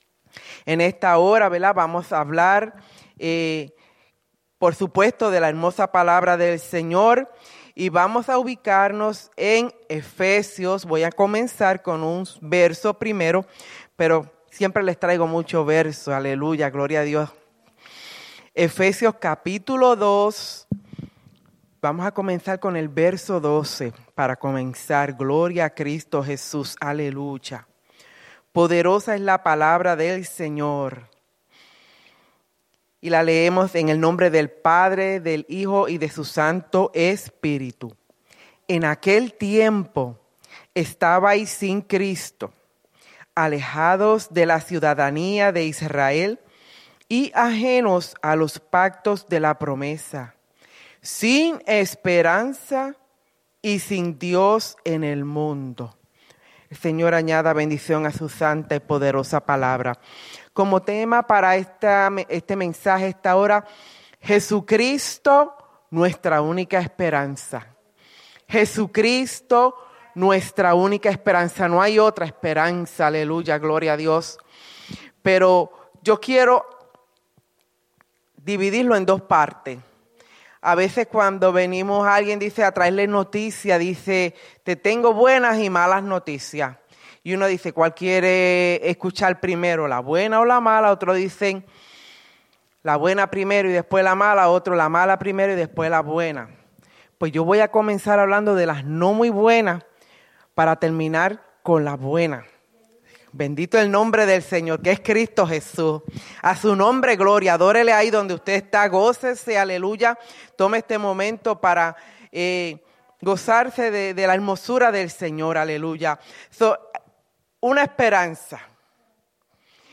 Iglesia Misión Evangélica